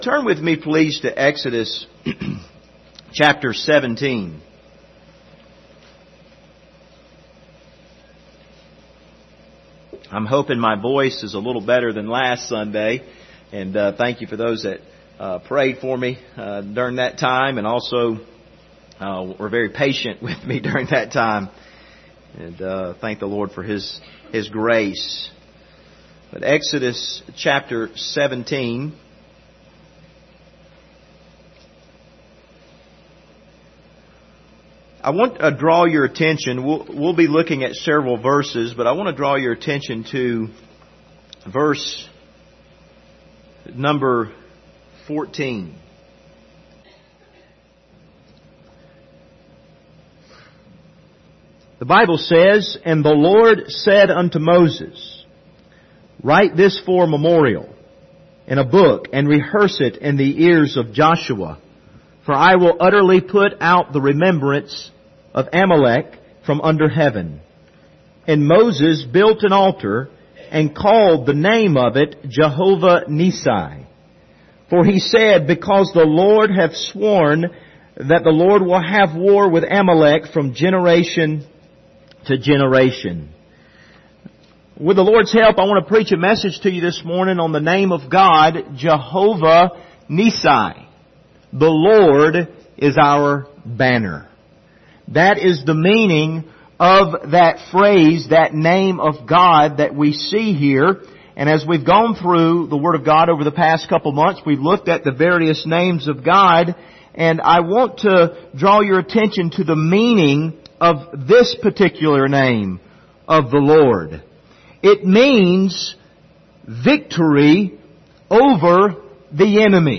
Passage: Exodus 17 Service Type: Sunday Morning